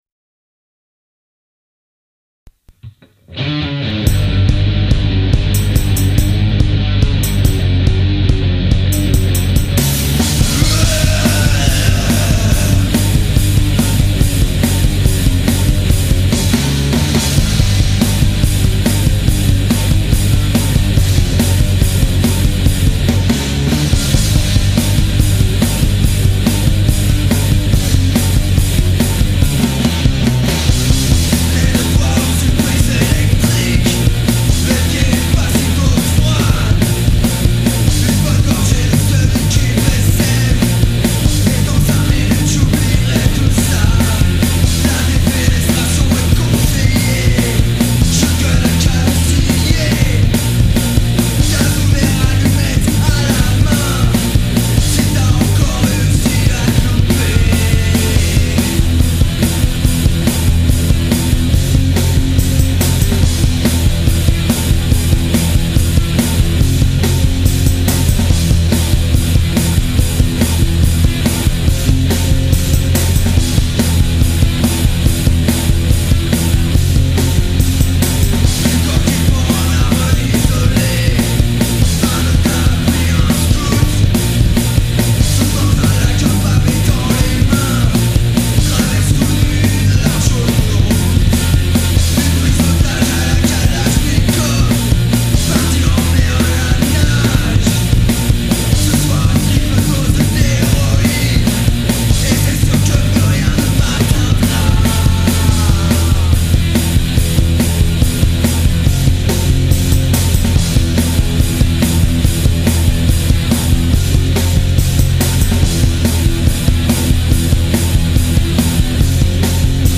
guitare voix boite à ryhtme